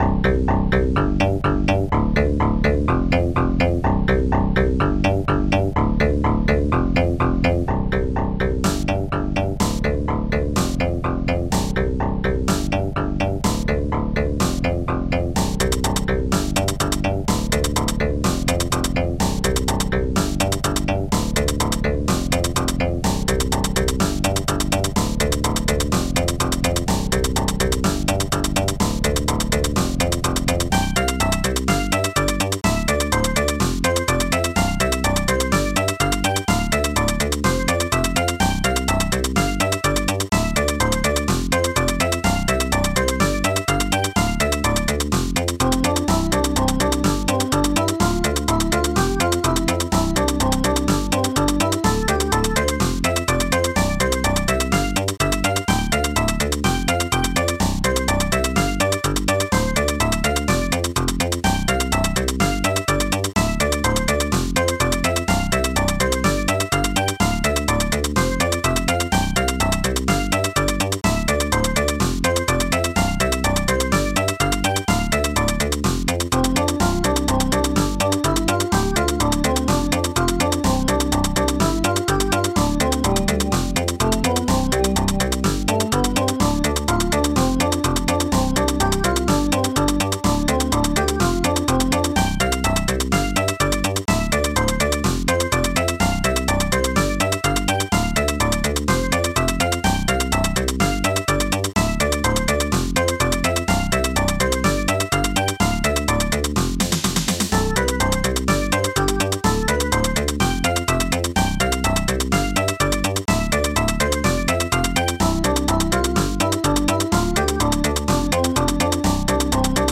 st-01:funkbass st-01:popsnare2 st-02:hihat3